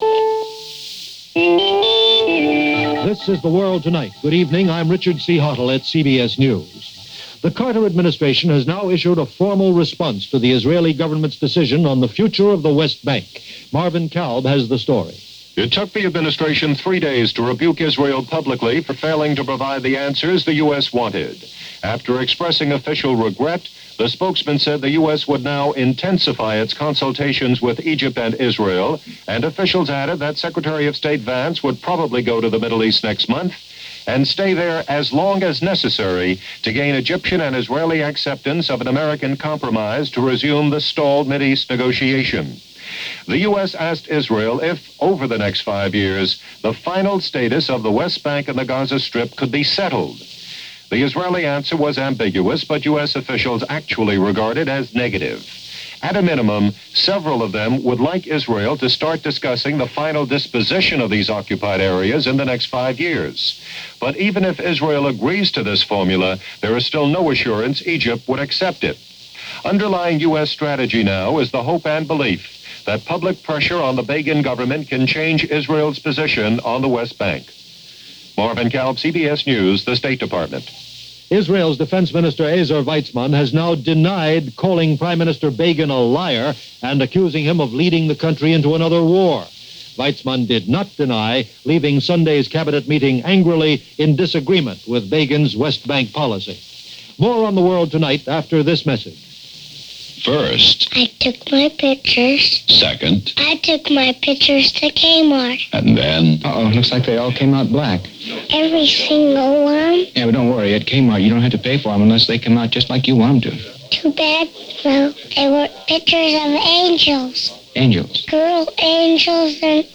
And along with continuing reports of aftershocks in Salonika, that’s a small slice of what happened, this June 21, 1978 as reported by The World Tonight from CBS Radio.